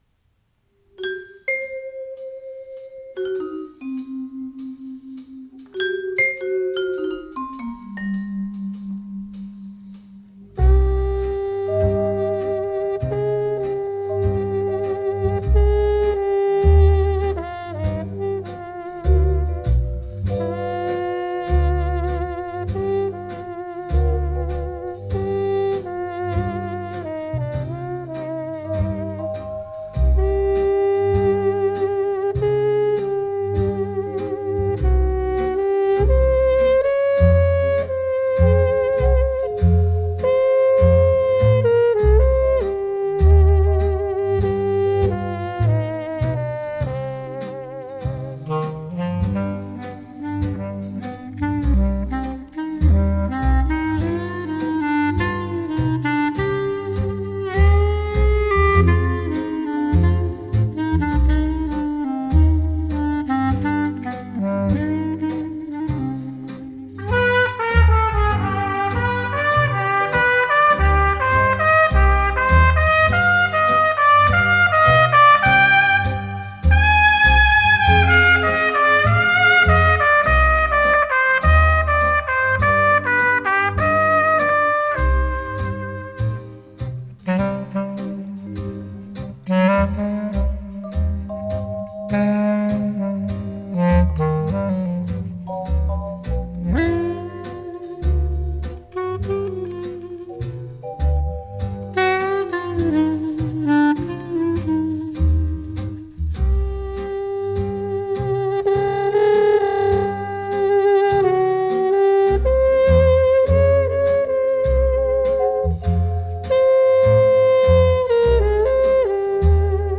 (trp.)
(medium-Foxtrptt)